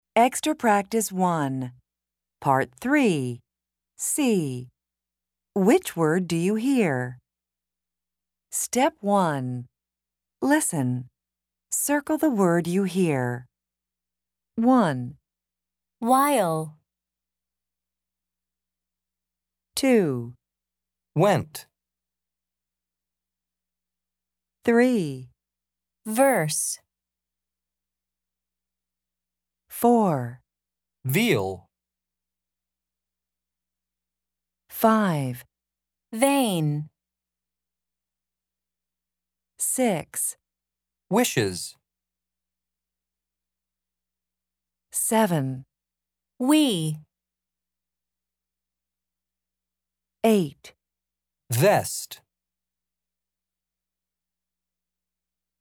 Pronunciation and Listening Comprehension in North American English
American English